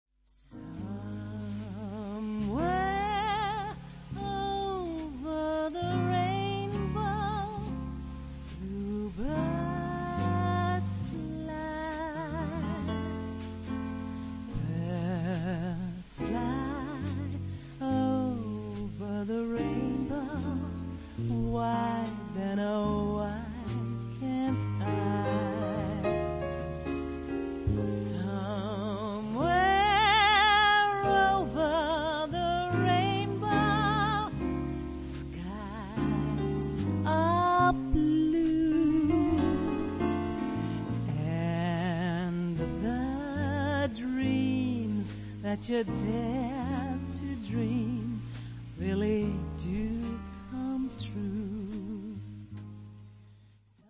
Wir freuen uns, diese hochkarätige Sängerin begleiten zu dürfen.
Klavier
Bass
Schlagzeug